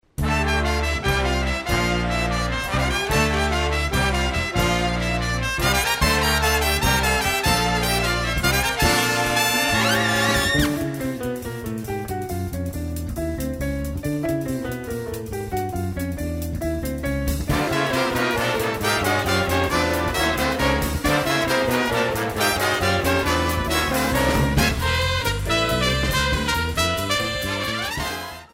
the applause and whistles and clinking of glasses.
A fast modern jazz number
piano
Meter shifts from 7/4 to 4/4 and a sizzling ending.